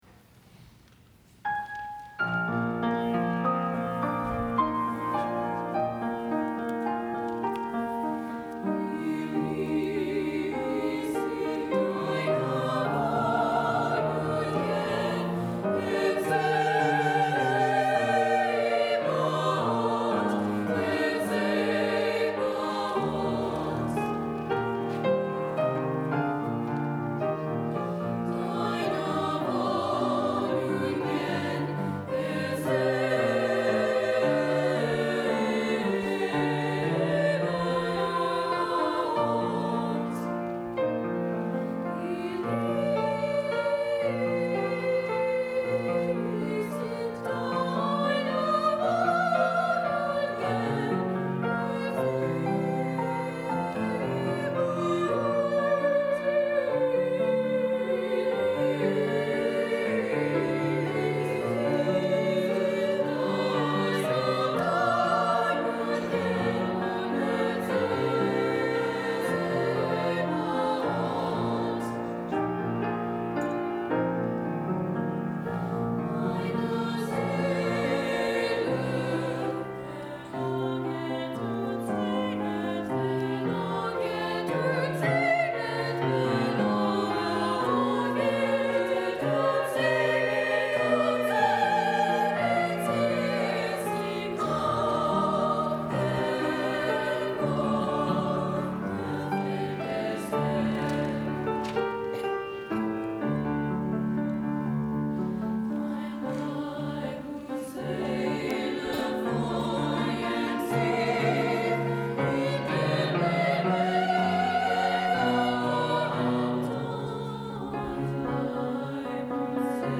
Voicing: SSA Choir